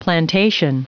Prononciation du mot plantation en anglais (fichier audio)
Prononciation du mot : plantation
plantation.wav